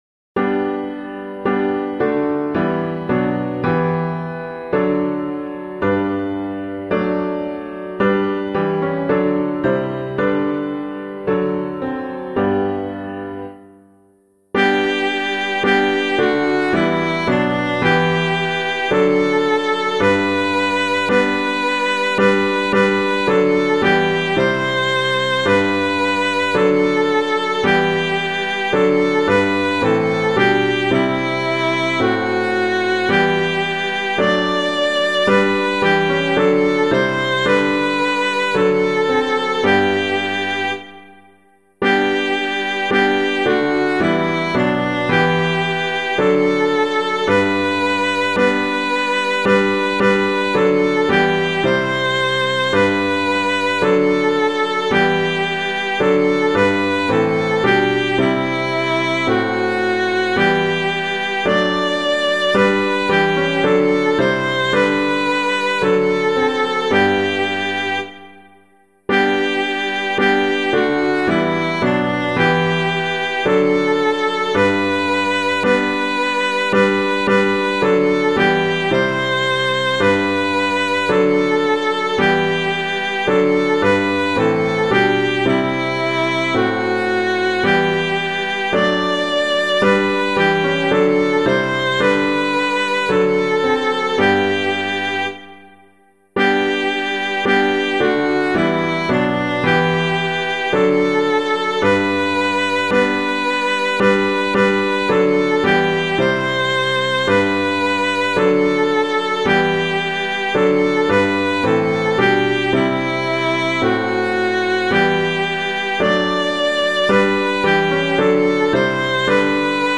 Let All on Earth Their Voices Raise [Mant - OLD 100TH] - piano.mp3